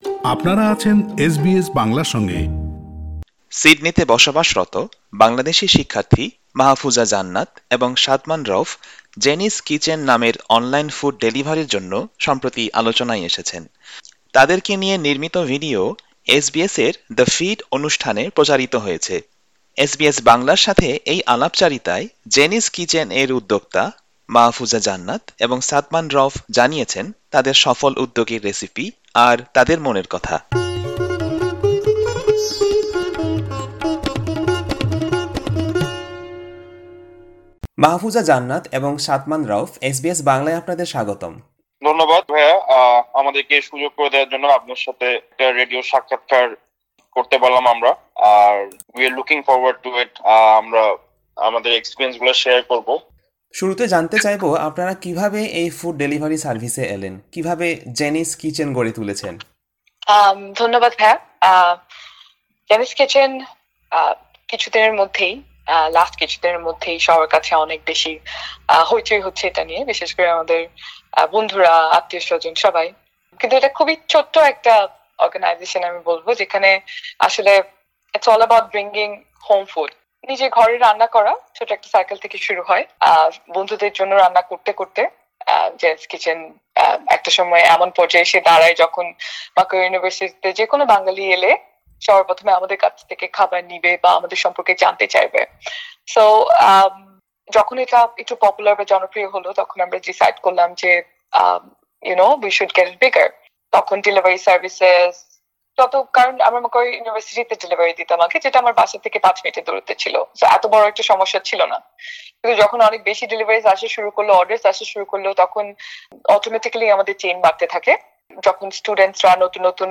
এসবিএস বাংলার সাথে এই আলাপচারিতায়